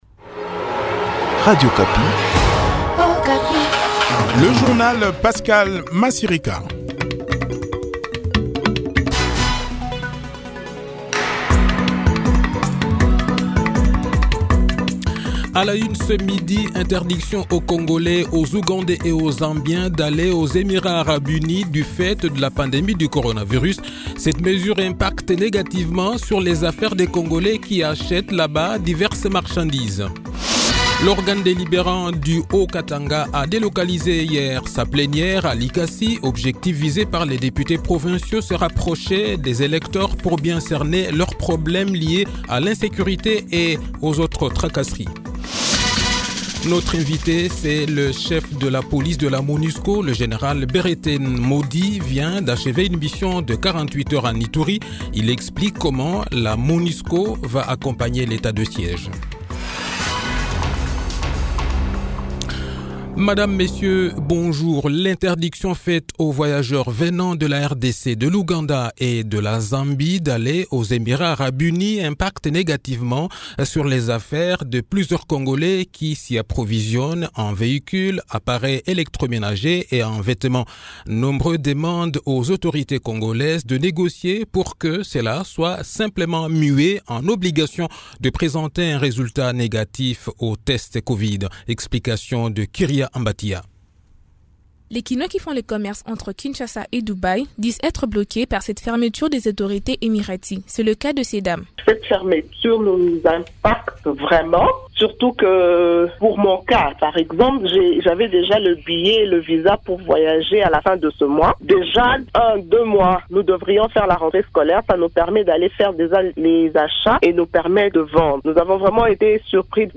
Le journal de 12 h, 24 Juin 2021